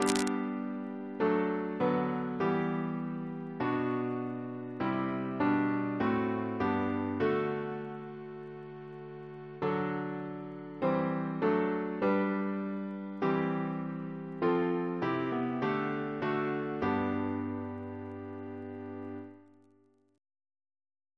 CCP: Chant sampler
Double chant in G Composer: William Crotch (1775-1847), First Principal of the Royal Academy of Music Reference psalters: ACP: 53; H1940: 655; H1982: S186 S229; PP/SNCB: 202